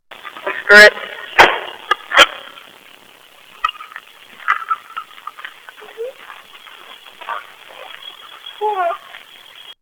This was an abandoned high school we had access to from the local police department.
EVPs
Giggle and groan
giggle.mp3